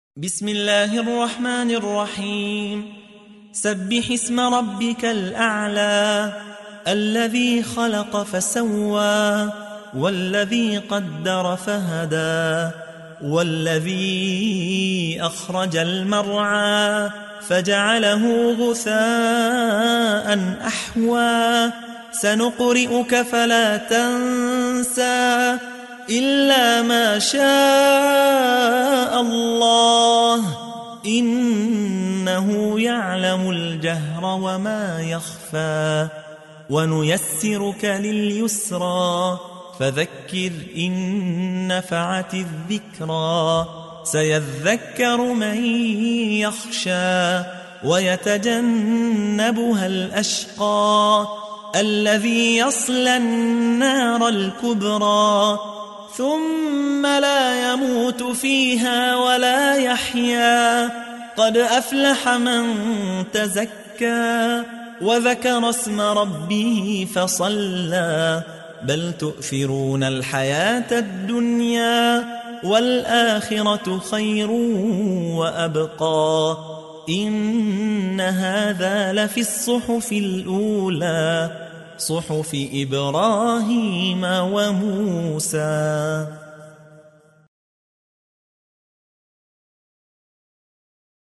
87. سورة الأعلى / القارئ